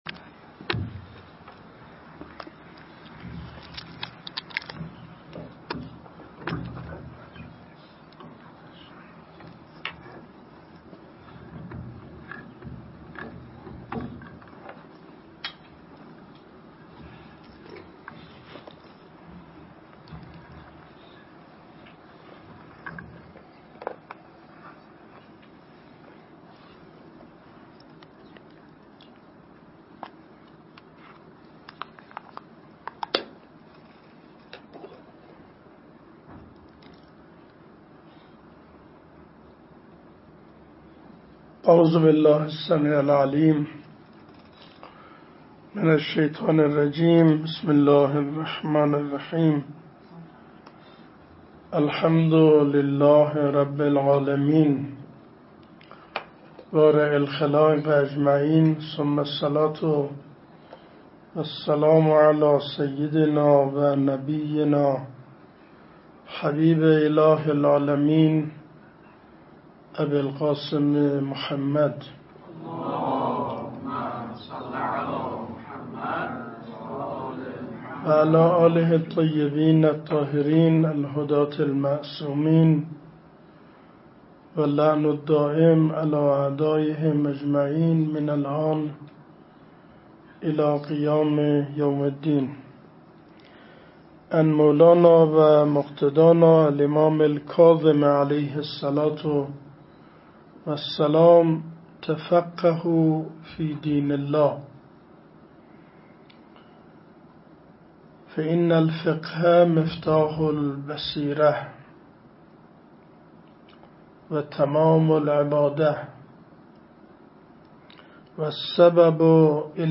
صوت سخنرانی آیت الله حسینی بوشهری در مراسم اختتامیه پنجمین جشنواره علمی سال1404
و توسل به ساحت نورانی امام حسین علیه السلام، همراه با تقدیر از برگزیدگان پنجمین جشنواره علمی در روز دوشنبه 13مرداد 1404 برگزار شد.